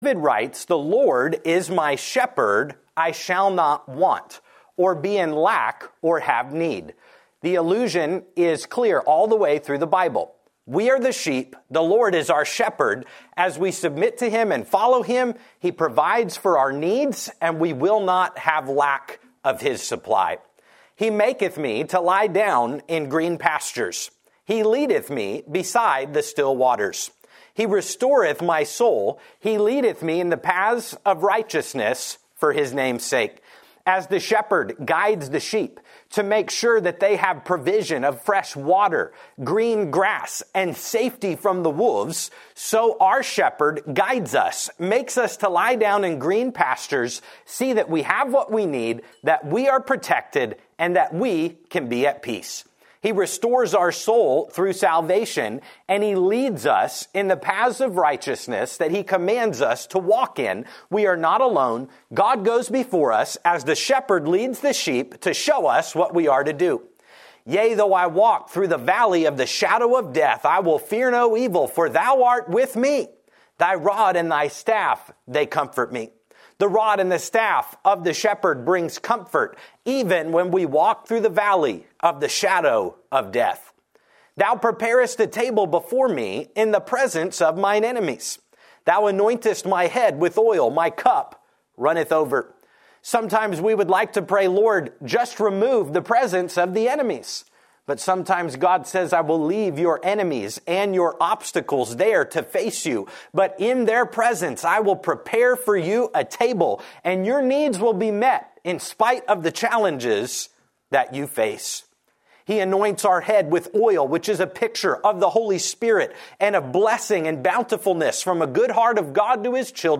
Sermons | Renewal Baptist Church